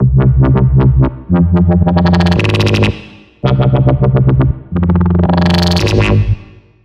描述：机器人DubStep BAZZ 02
Tag: 140 bpm Dubstep Loops Bass Loops 1.15 MB wav Key : Unknown